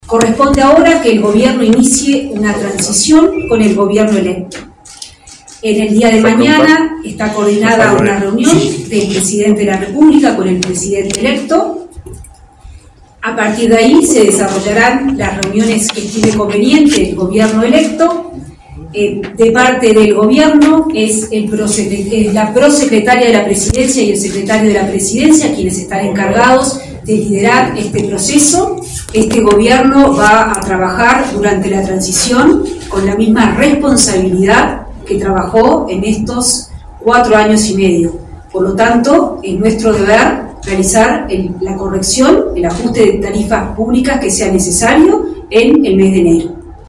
“El ajuste de tarifas del primero de enero le corresponderá a este gobierno”, dijo en la conferencia de prensa, donde Arbeleche fue vocera, tras el consejo de ministros.